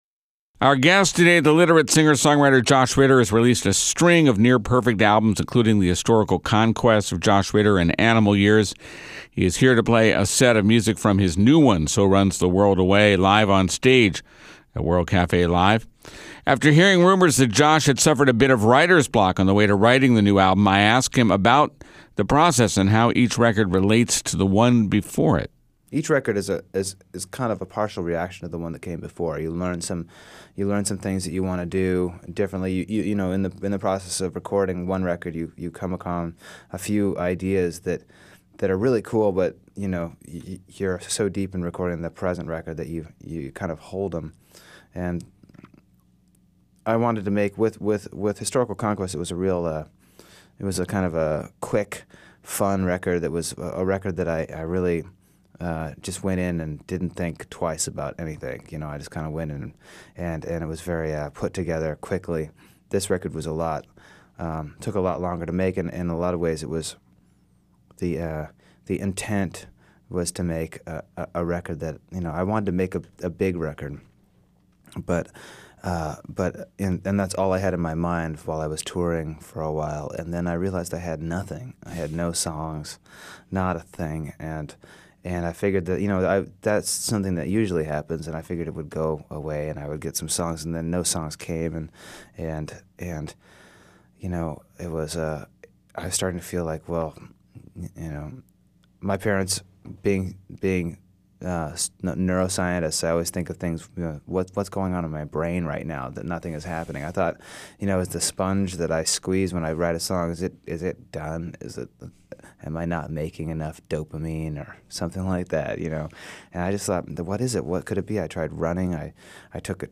Influenced by classic rock and folk songwriters